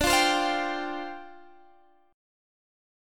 Dm11 Chord (page 3)
Listen to Dm11 strummed